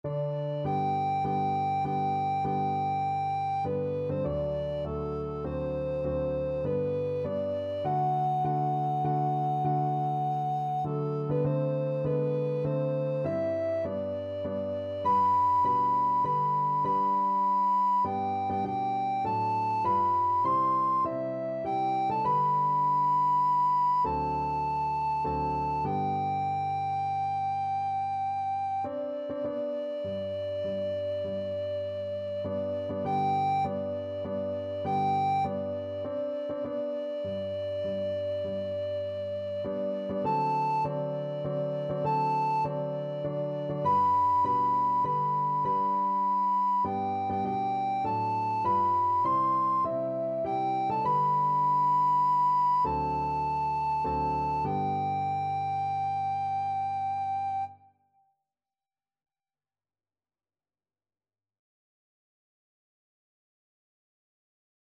Christian Christian Alto
3/4 (View more 3/4 Music)